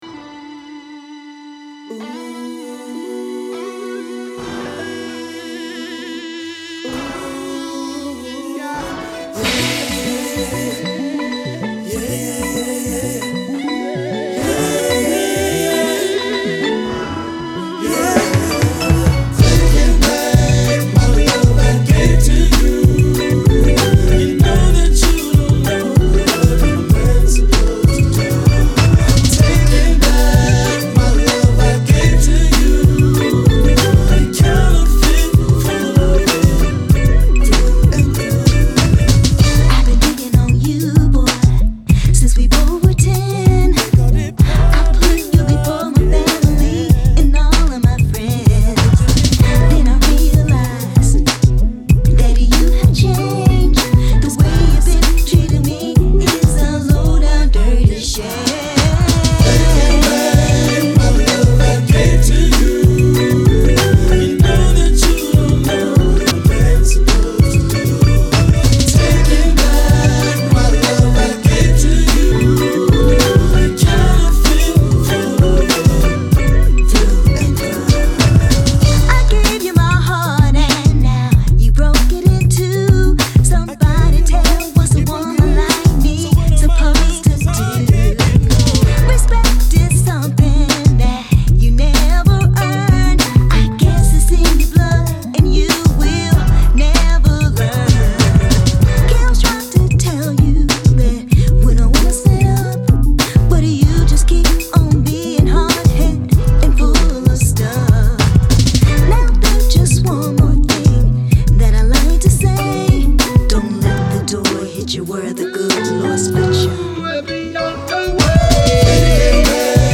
RnB
This funky track features background vocals
a vocal obbligato